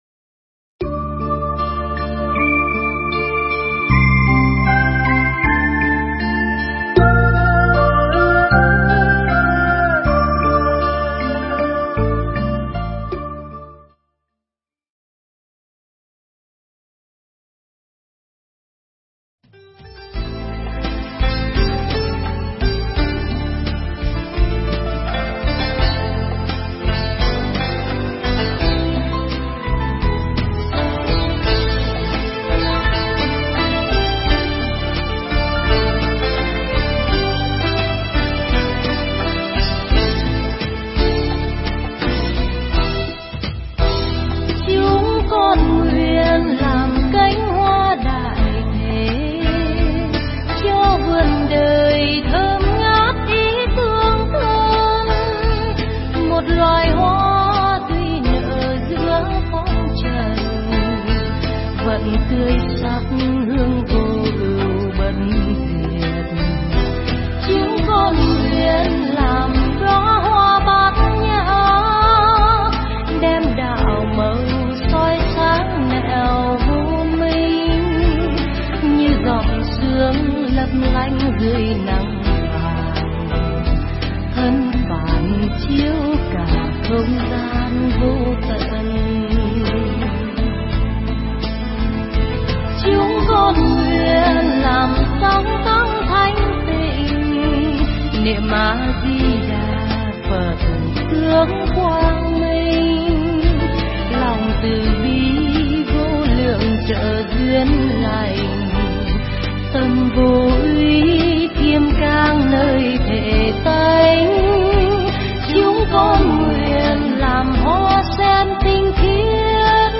Nghe Mp3 thuyết pháp Không Tìm Lỗi Người
Mp3 pháp thoại Không Tìm Lỗi Người